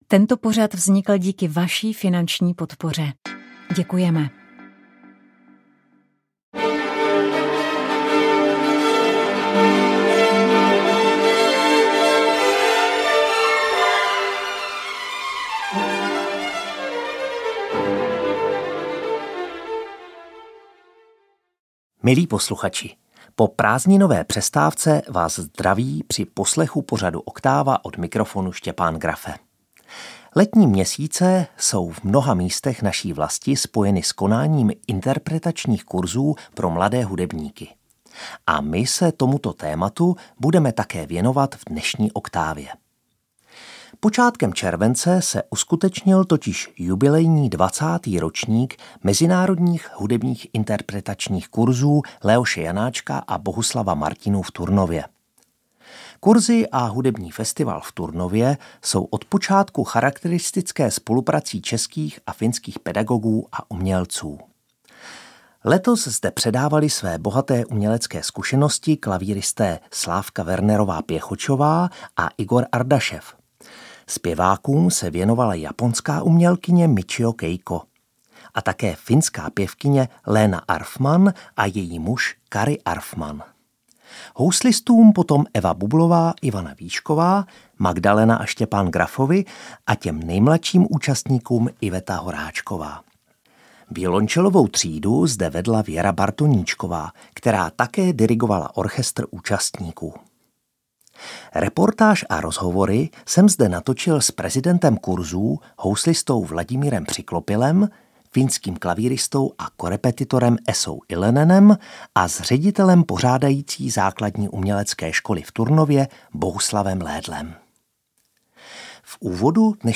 V dalším vánočním pořadu pojednáme o koledách, zvláště o tvorbě profesora Bedřicha Antonína Wiedermanna v této oblasti. Přiblížíme si rovněž jeho osobnost a zazní originální nahrávky autorových koled, pořízené speciálně pro Radio Proglas v Kostelci na Hané.
Tamní kostel sv. Jakuba Staršího, kde se loni v létě natáčelo, oslavil právě 250 let existence.